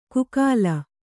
♪ kukāla